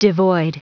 Prononciation du mot devoid en anglais (fichier audio)
Prononciation du mot : devoid